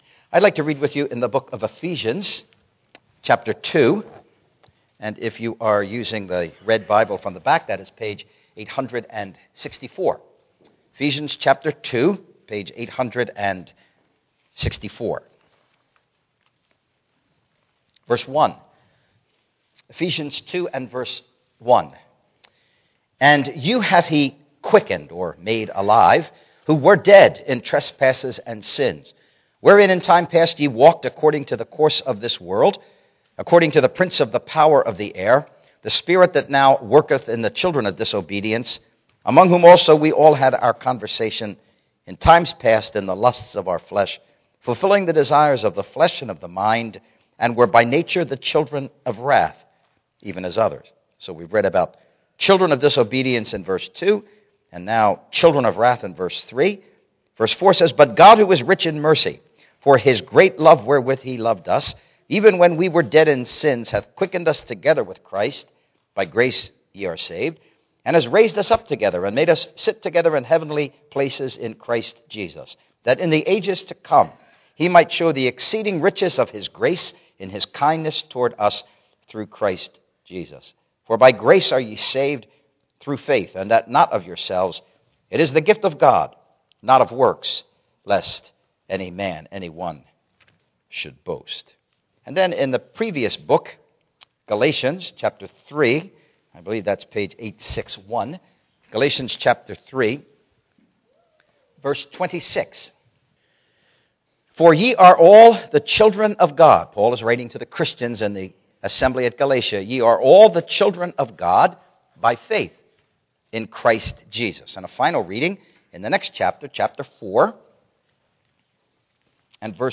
Series: The Impact of The Bible and Christianity on World History Service Type: Gospel Preaching